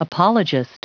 Prononciation du mot apologist en anglais (fichier audio)
Prononciation du mot : apologist